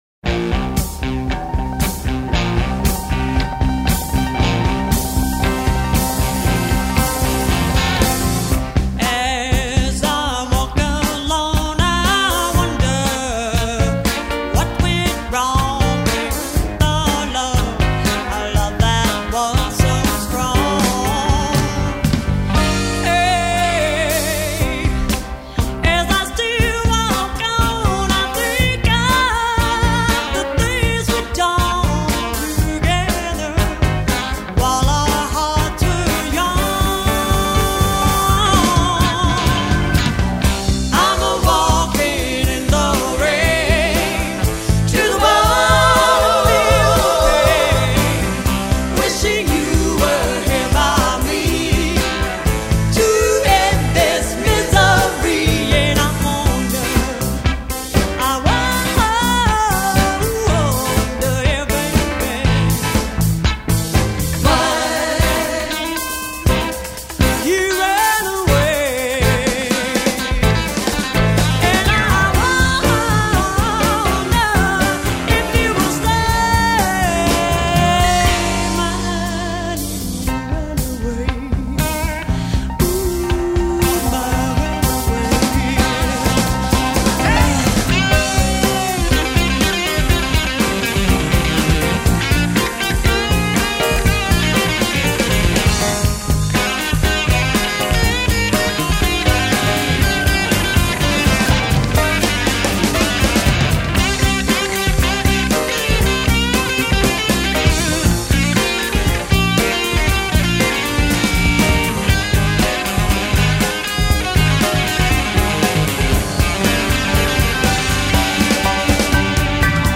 Live at Neds(1978)